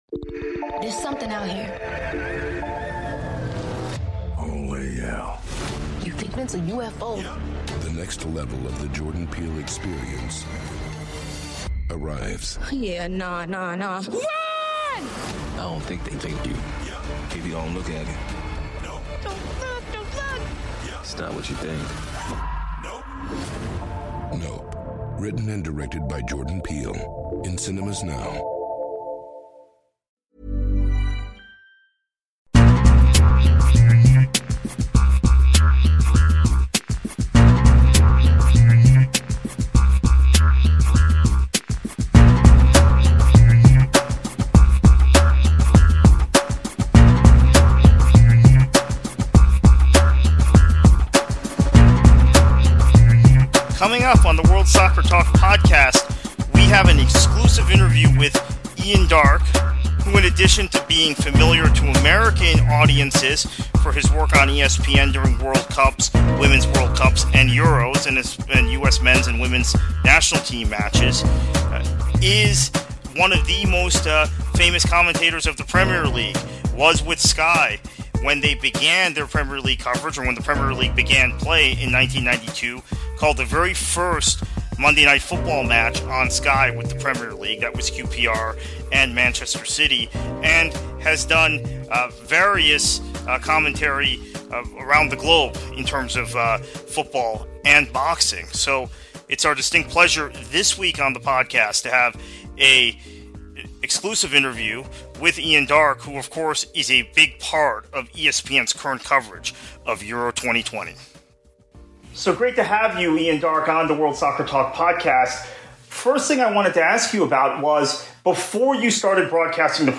Ian Darke Interview: ESPN Commentator Discusses His Career On The World's Stage